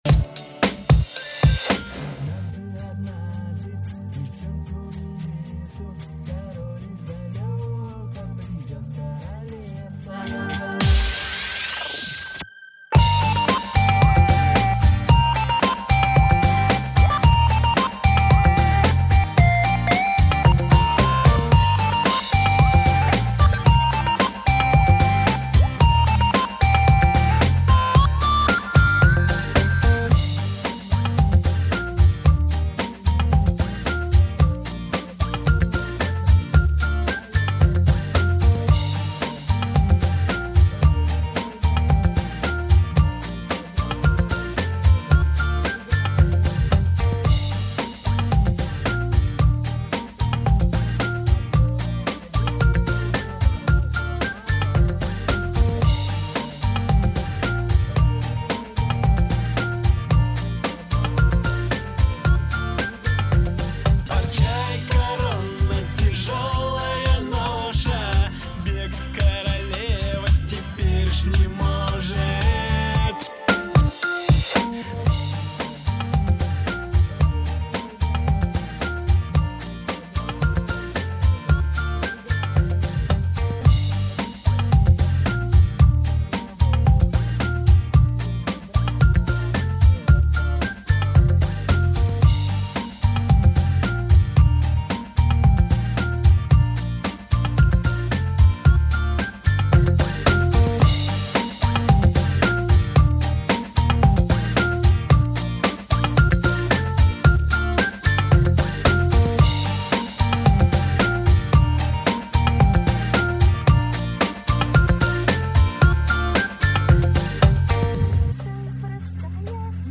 Свадебные